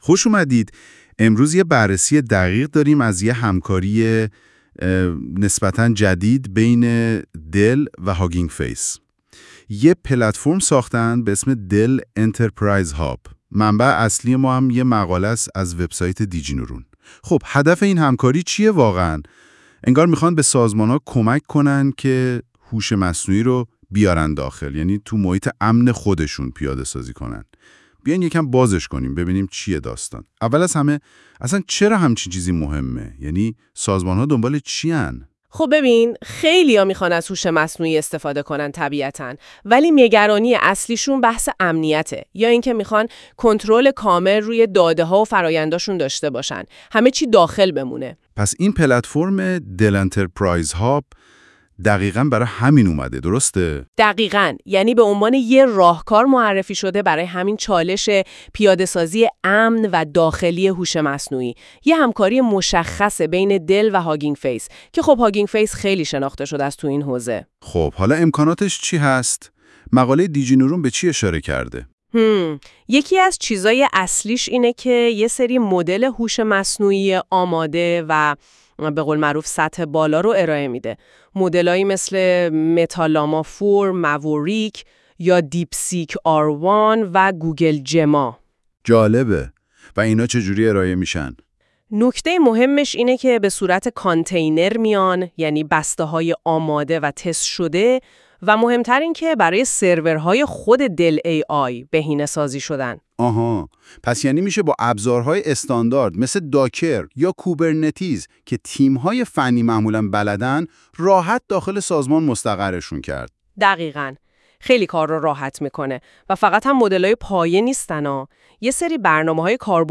پادکست زیر را می توانید استفاده کنید (ساخته شده با هوش مصنوعی):